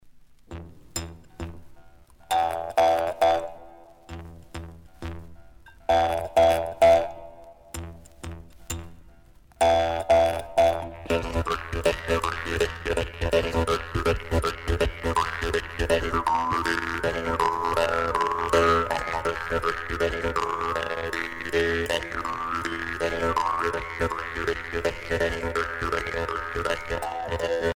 Tarentelle de l'ouest sicilien
danse : tarentelle
Les guimbardes